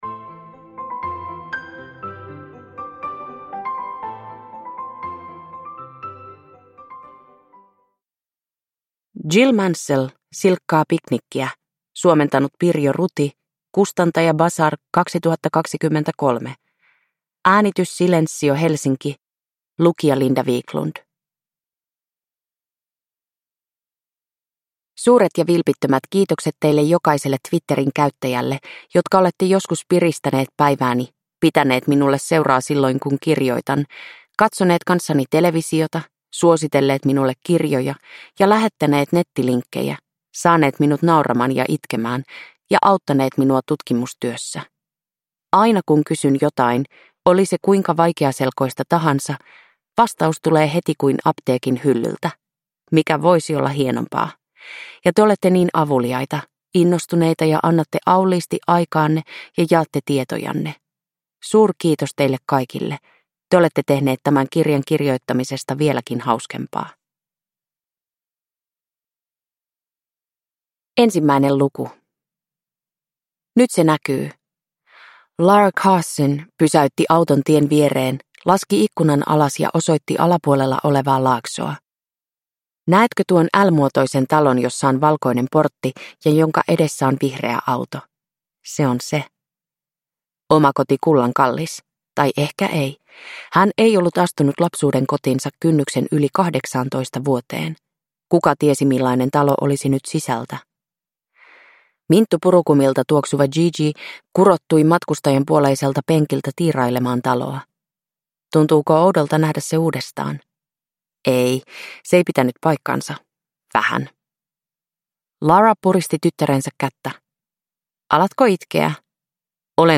Silkkaa piknikkiä – Ljudbok – Laddas ner